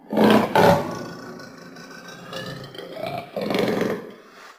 sounds_leopard_snarl_01.ogg